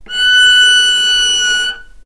vc-F#6-mf.AIF